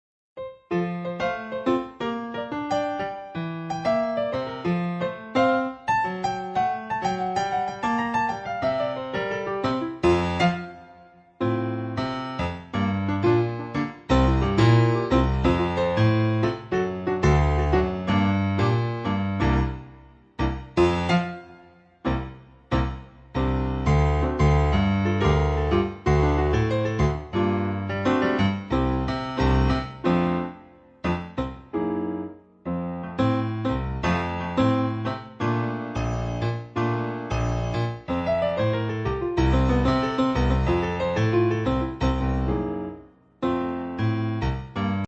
Klarinette und Klavier